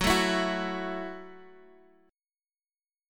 Gbm7 Chord (page 3)
Listen to Gbm7 strummed